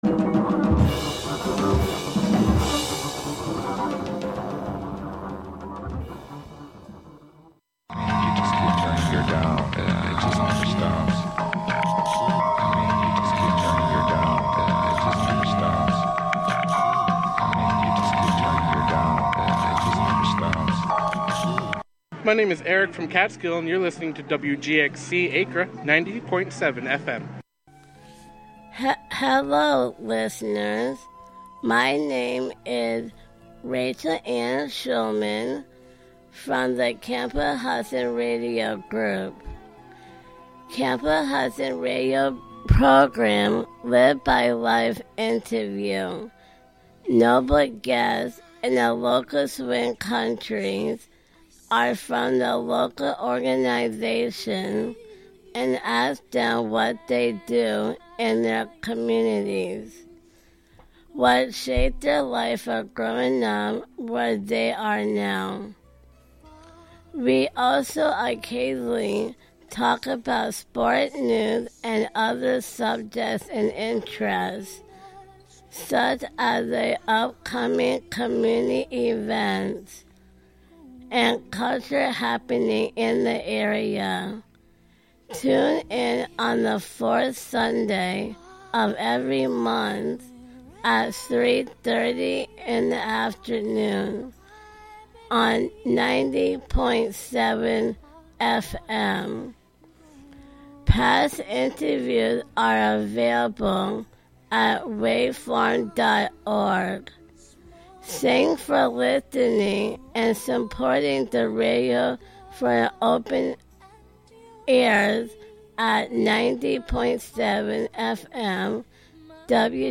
An upbeat music show featuring the American songbook as interpreted by contemporary artists as well as the jazz greats in a diverse range of genres live from WGXC's Catskill studio.